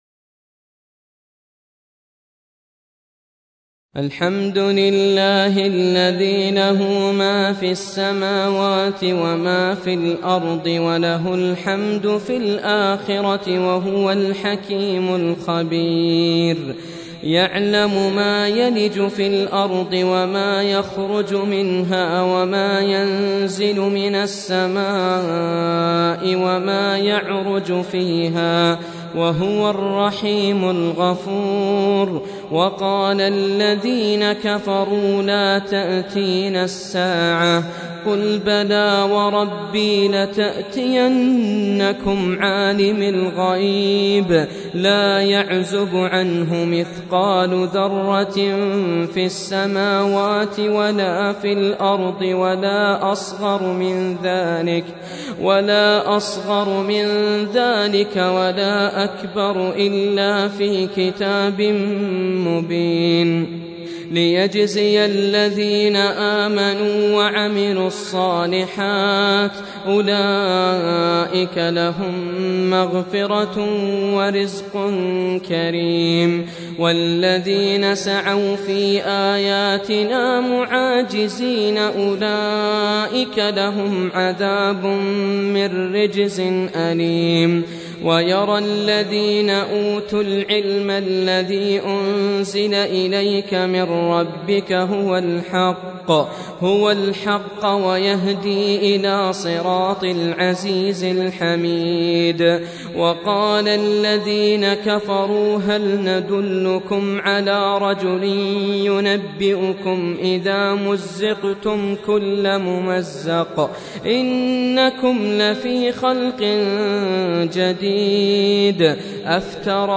Surah Repeating تكرار السورة Download Surah حمّل السورة Reciting Murattalah Audio for 34. Surah Saba' سورة سبأ N.B *Surah Includes Al-Basmalah Reciters Sequents تتابع التلاوات Reciters Repeats تكرار التلاوات